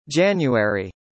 ˈʤænjuˌɛri
英語の「月」の発音集